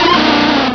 pokeemerald / sound / direct_sound_samples / cries / dunsparce.aif